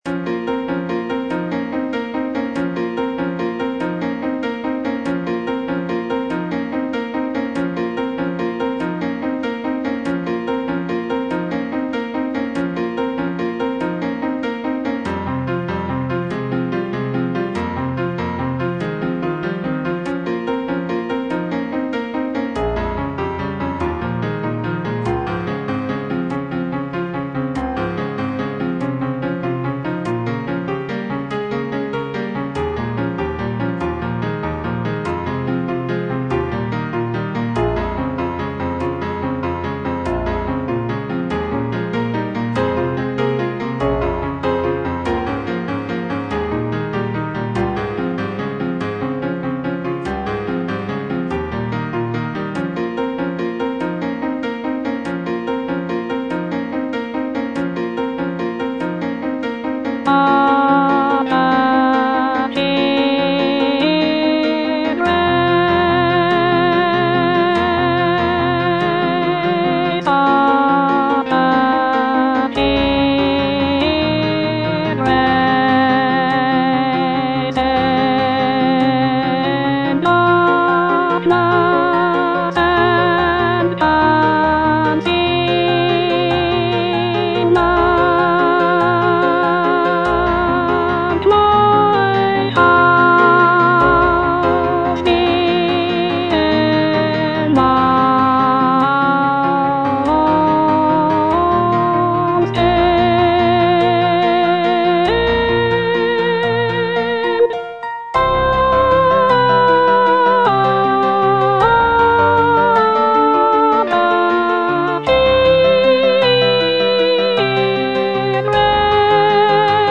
soprano II) (Voice with metronome) Ads stop